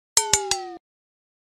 เสียงเตือนแบตเตอรี่ต่ำ ไอโฟน (ตอนโทร)…
คำอธิบาย: ด้านบนนี้คือเสียงแจ้งเตือนเมื่อแบตเตอรี่ใน iPhone ต่ำ ขณะที่ใช้โทรศัพท์/ iPhone low battery sound (during phone call) หรือเมื่อใช้หูฟังไร้สาย Apple AirPods หากคุณต้องการนำเสียงนี้มาใช้ในการแก้ไขวิดีโอ กรุณาดาวน์โหลดผ่านลิงก์ที่อยู่ที่ด้านล่างของบทความนี้
am-thanh-pin-yeu-iphone-khi-goi-dien-thoai-www_tiengdong_com.mp3